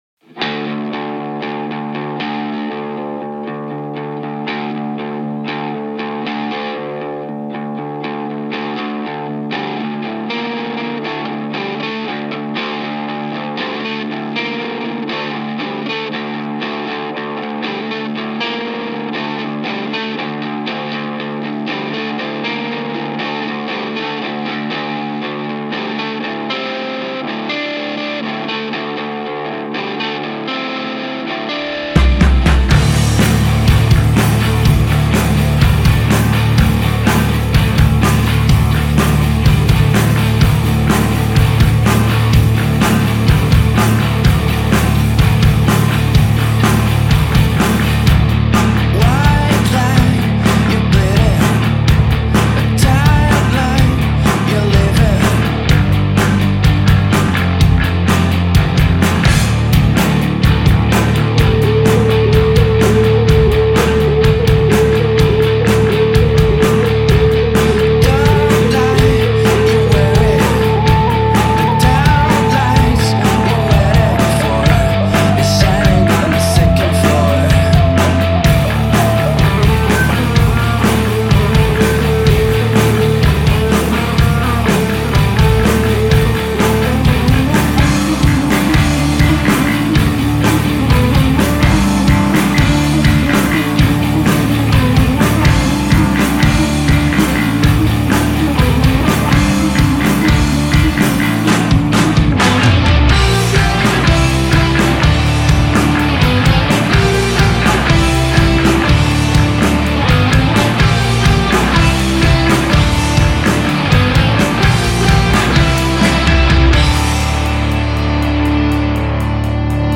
four piece Parisian rock band